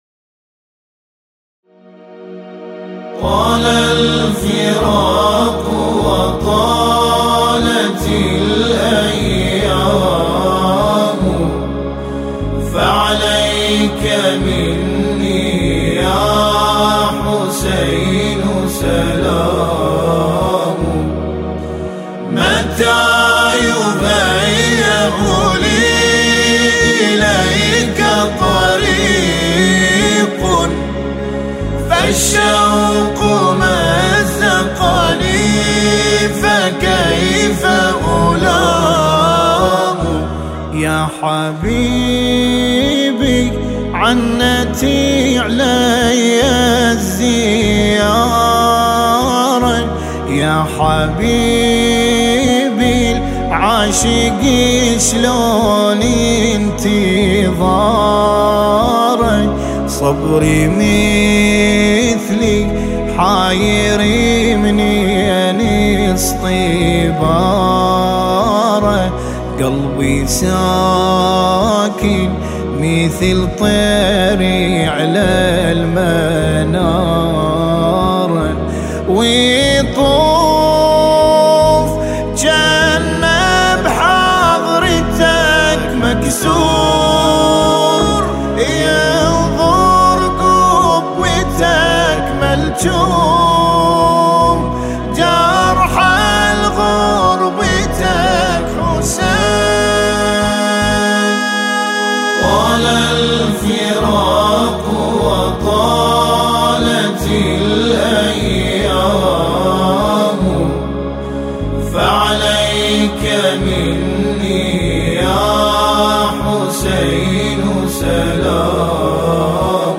مرثیه‌ای